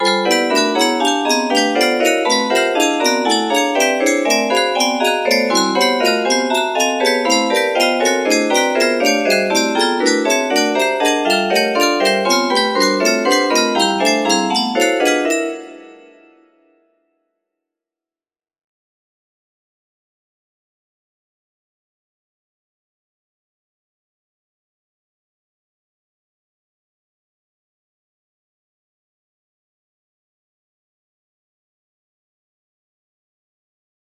P9 music box melody